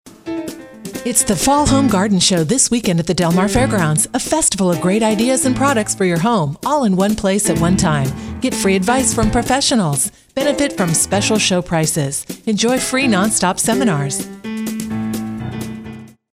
Commercial Friendly
My voiceover style is consistently described as authentic, calm, and trustworthy, offering a smooth, conversational and friendly delivery that truly connects.
Operating from a professional home studio utilizing Adobe Audition, I meticulously record, produce and deliver pristine, polished voiceover recordings with exceptional attention to detail.